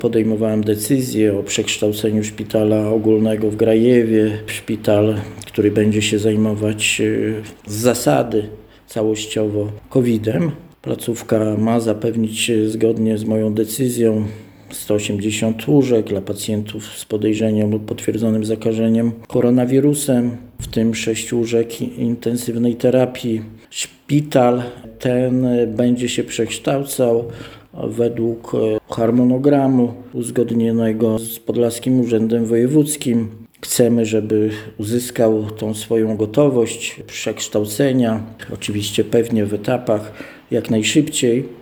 Jak powiedział Radiu 5 wojewoda podlaski Bohdan Paszkowski, zależy mu na tym, by szpital osiągnął gotowość jak najszybciej.